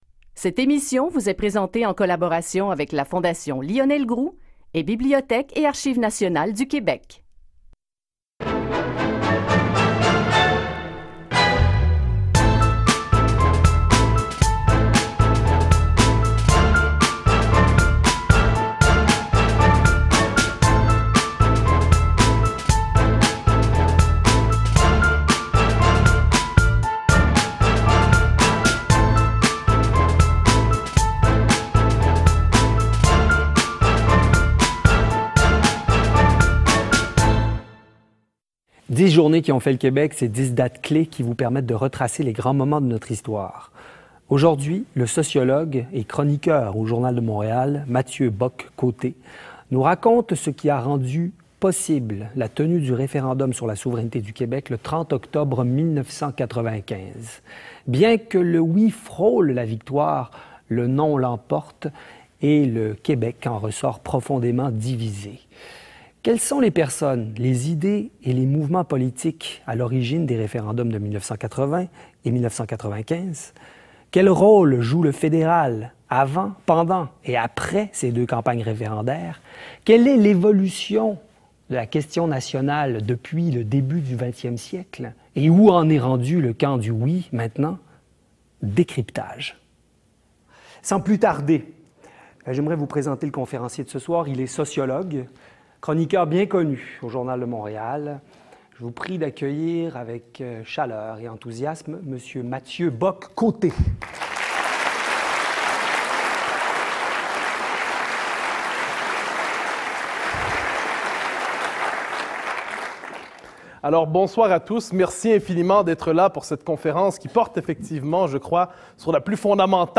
Dans cette dixième et dernière conférence de la série Dix journées qui ont fait le Québec, il s’agira de comprendre à la fois le référendum du 30 octobre 1995, le contexte qui